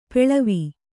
♪ peḷavi